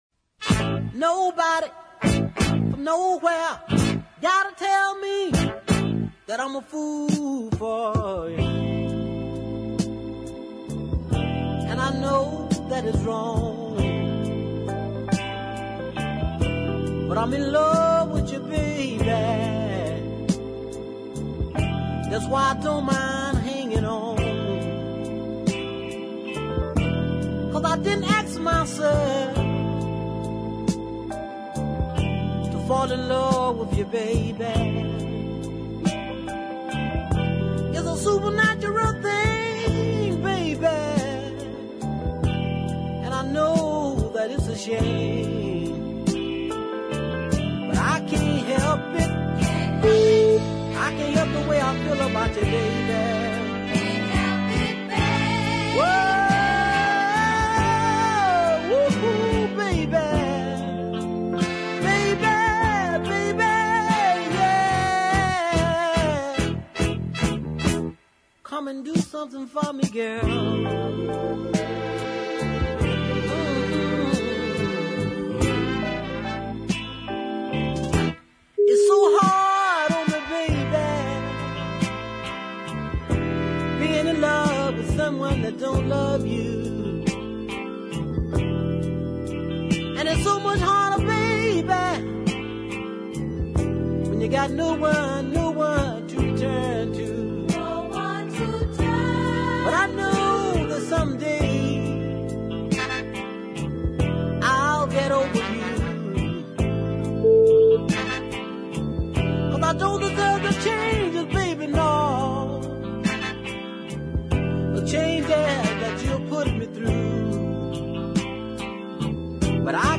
Light voiced Georgian singer